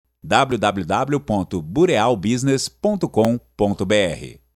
Está em anexo a pronúncia do nome da empresa e a pronúncia do site